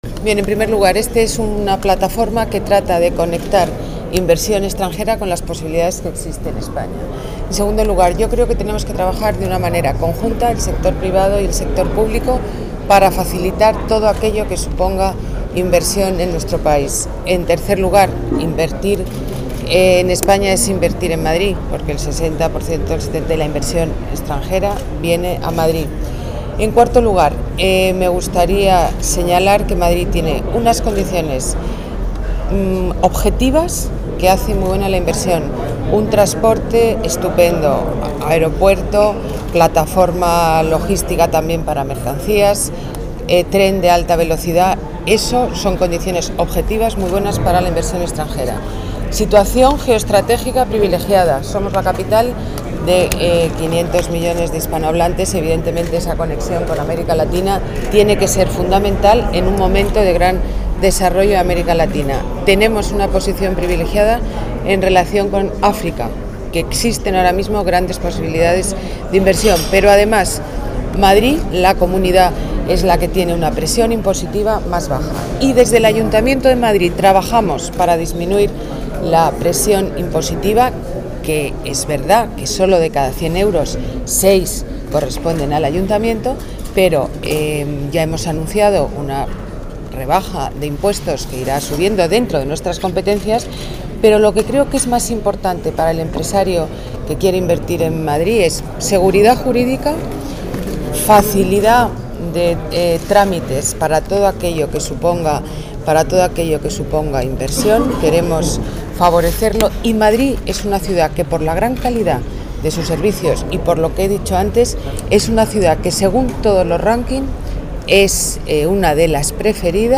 Nueva ventana:Declaraciones alcaldesa de Madrid, Ana Botella: inversiones extranjeras en Madrid